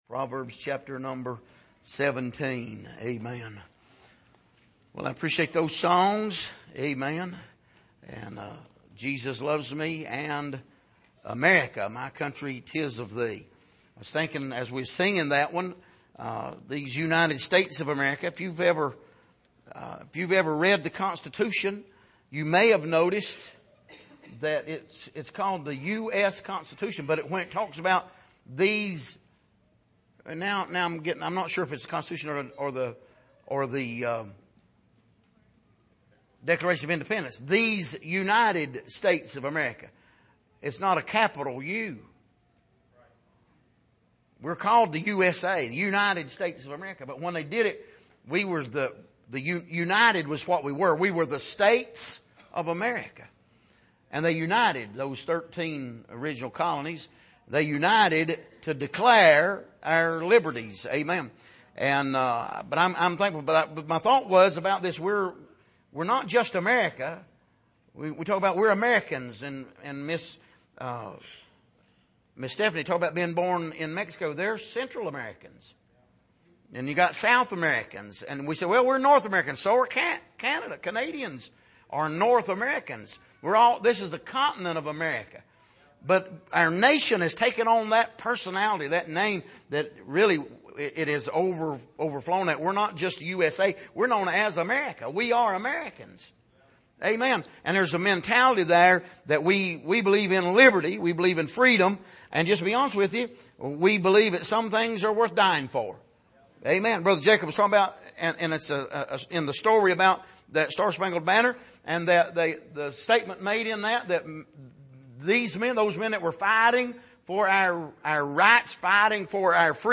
Passage: Proverbs 17:15-21 Service: Sunday Evening